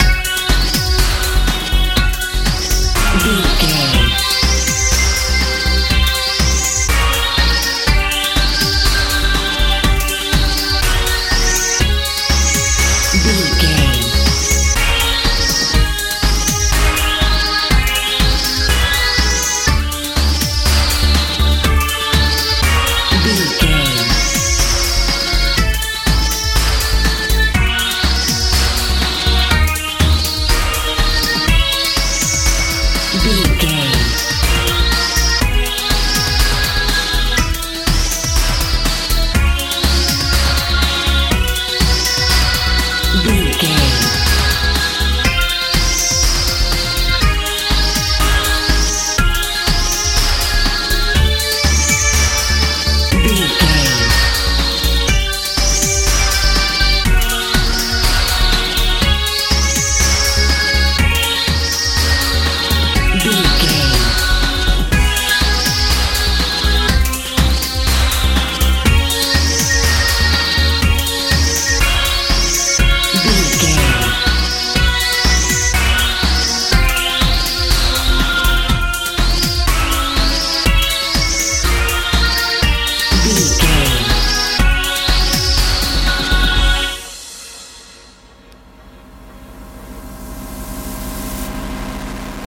euro dance feel
Ionian/Major
F♯
futuristic
powerful
bass guitar
synthesiser
drums
strange